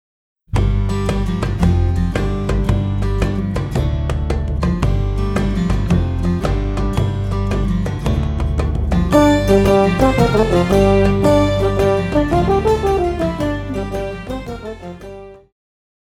Pop
French Horn
Band
Instrumental
World Music,Electronic Music
Only backing